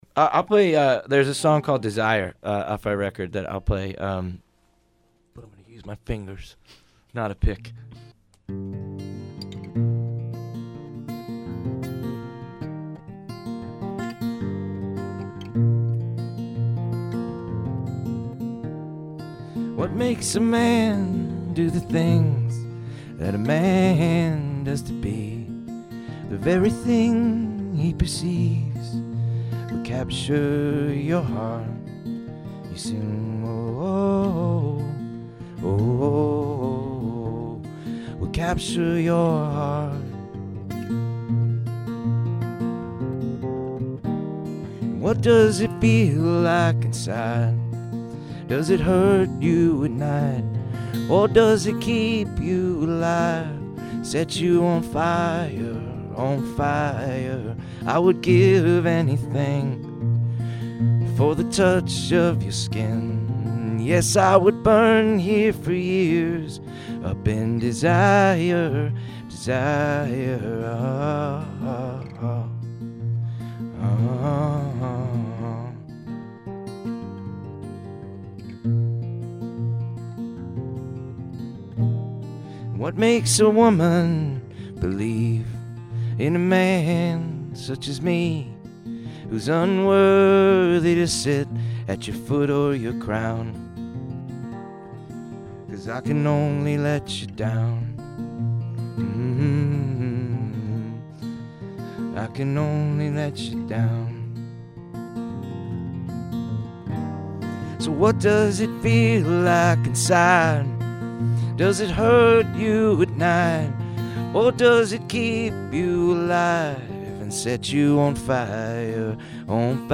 Very pretty.